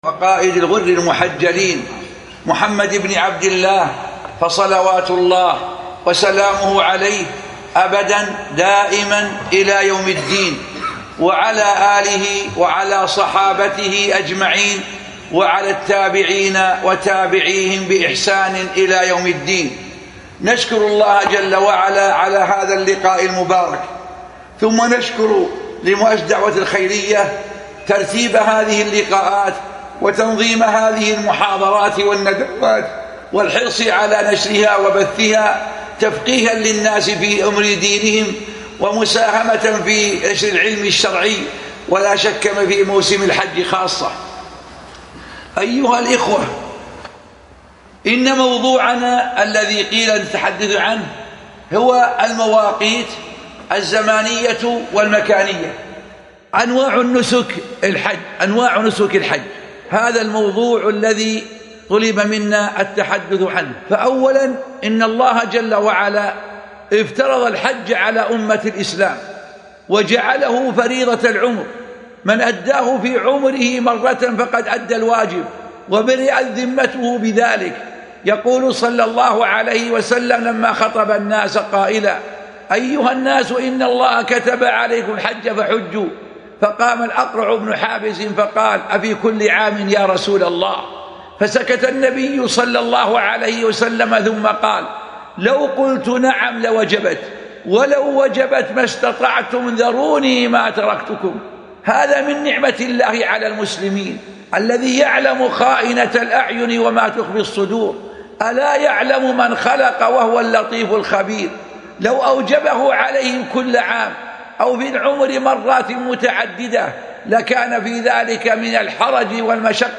شبكة المعرفة الإسلامية | الدروس | لمواقيت الزمانيه والمكانية وانواع النسك |عبد العزيز آل الشيخ